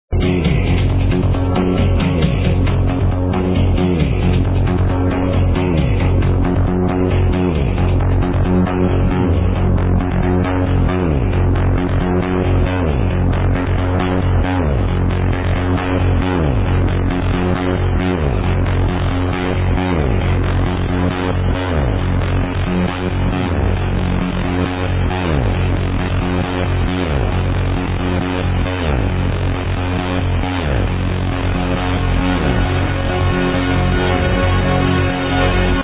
PROG TRANCE circa 1999-2000????